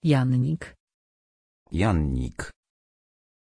Aussprache von Yannik
pronunciation-yannik-pl.mp3